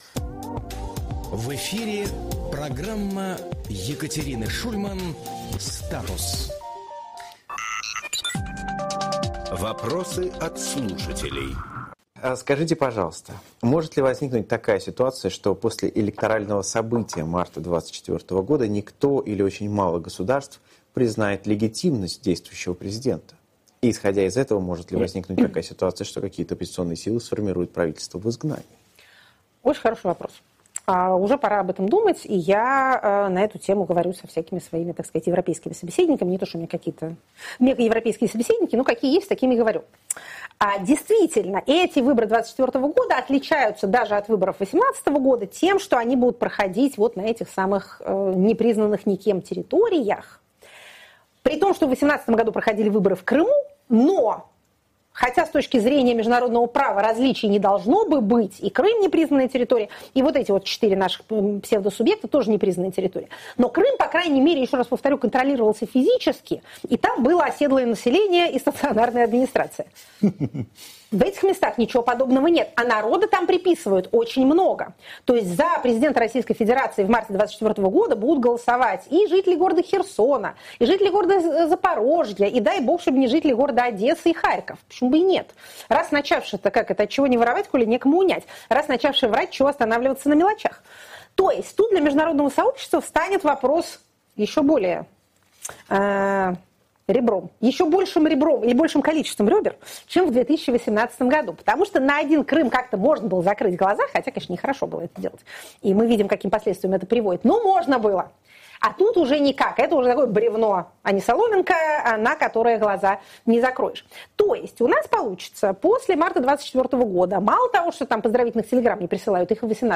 Екатерина Шульманполитолог
Фрагмент эфира от 4 октября.